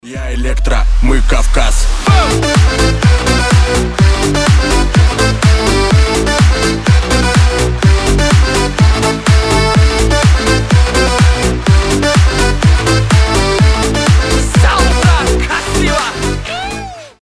• Качество: 320, Stereo
зажигательные
веселые
аккордеон
энергичные
кавказские
Шуточная танцевальная песня